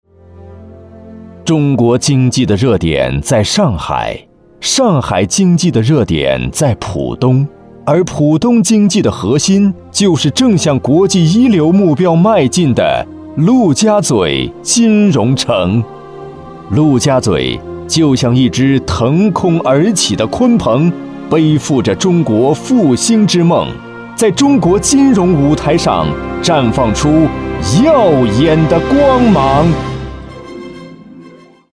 • 14专业男声4
专题解说-激昂大气